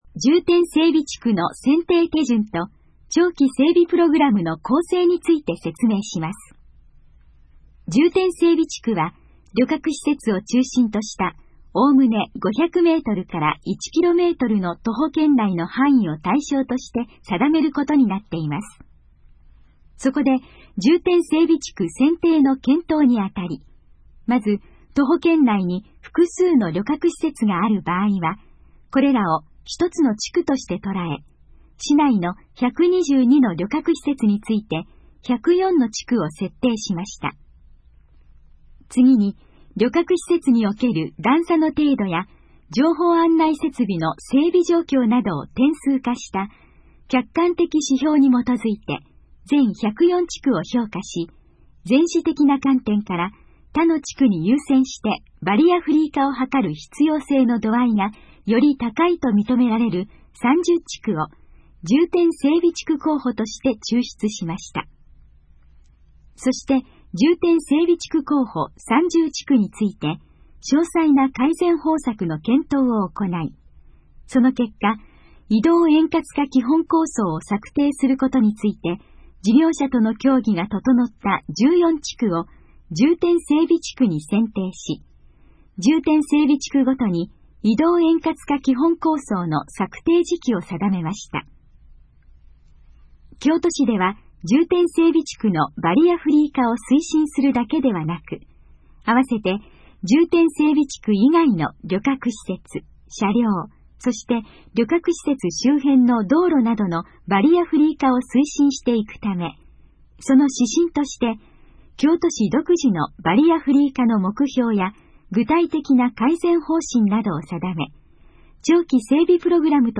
このページの要約を音声で読み上げます。
ナレーション再生 約319KB